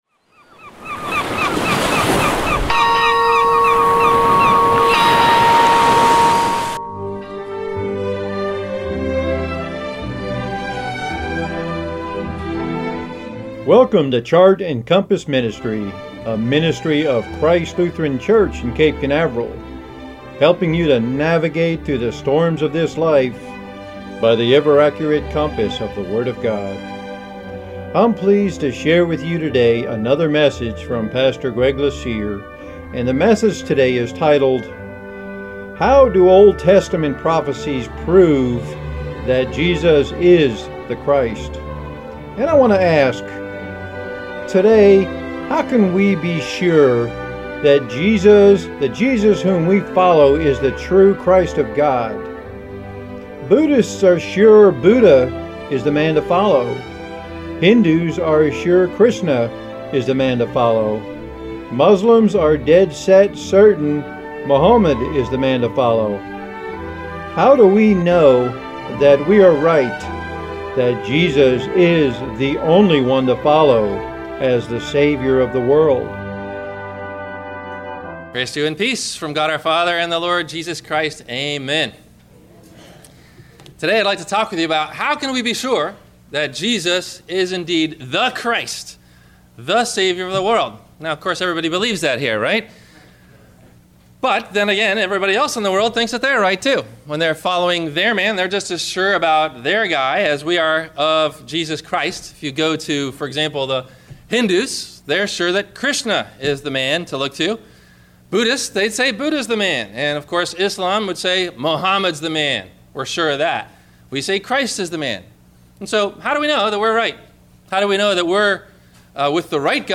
How do Old Testament Prophecies Prove that Jesus is the Christ? – WMIE Radio Sermon – December 28 2015